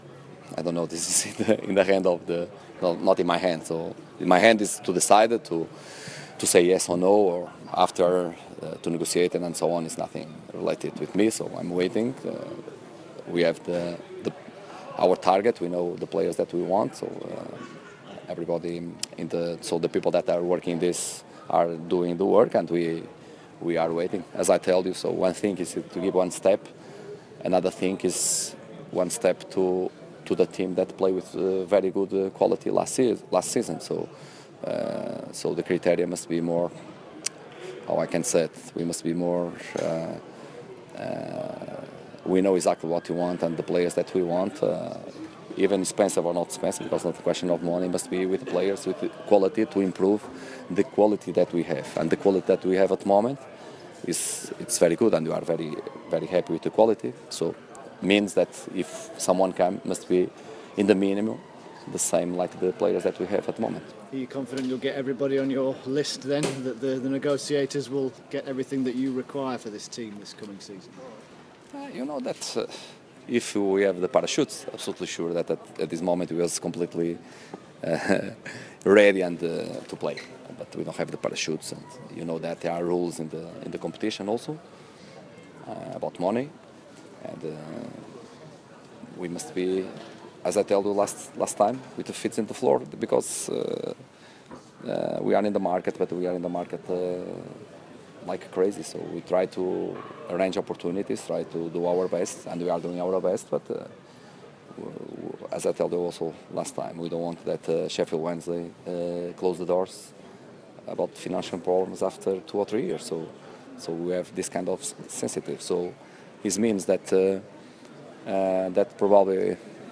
Sheffield Wednesday head coach Carlos Carvahal talks to members of the media